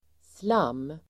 Uttal: [slam:]